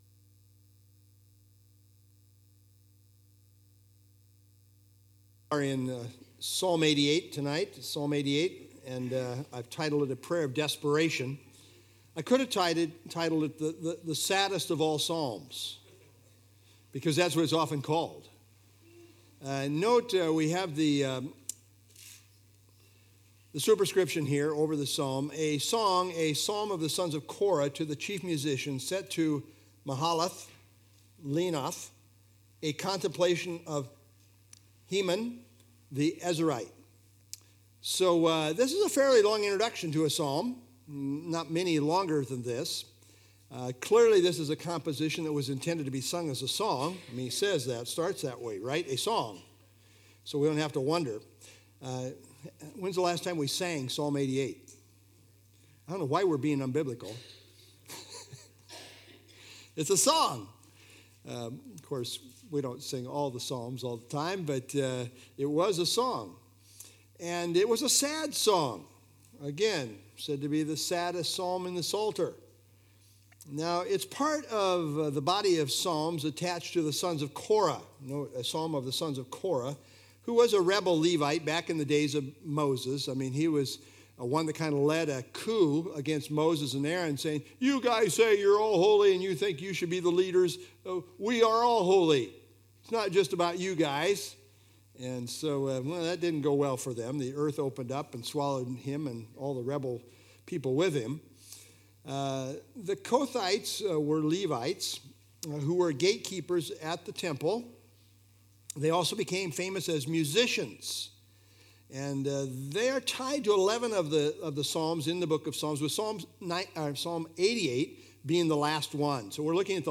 Download FilesPsalm 88 Sermon - Dec 7 2025Psalm 88